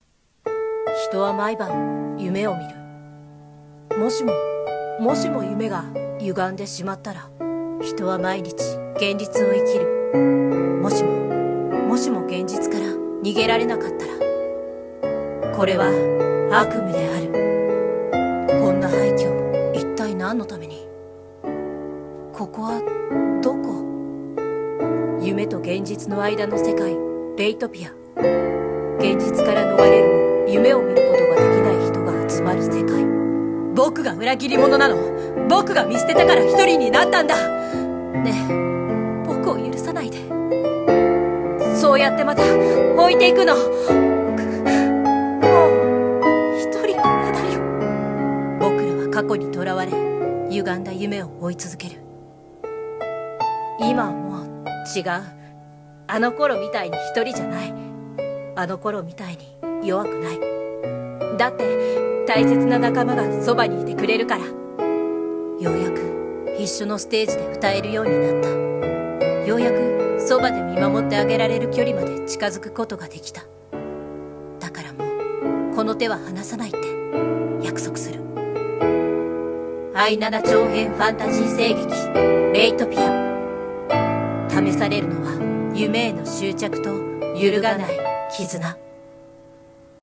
【声劇】アイナナRatoPiaCM